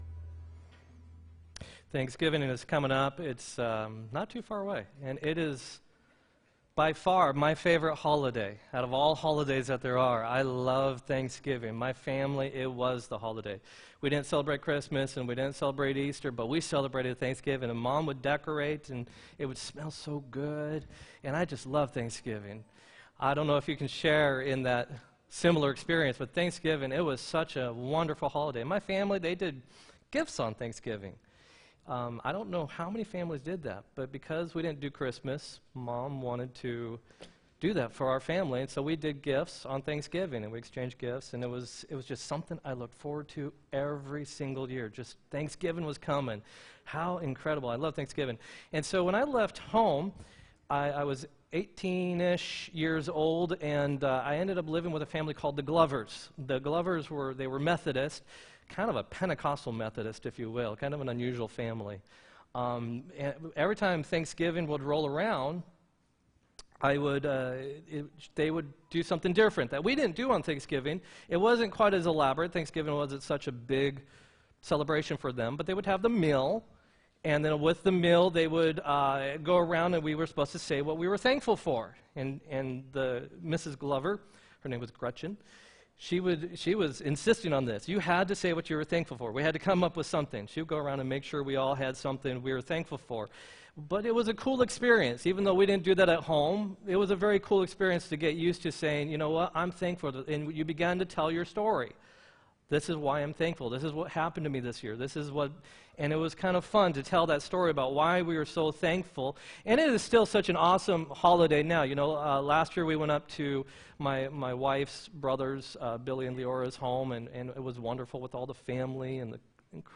11-4-17 sermon
11-4-17-sermon.m4a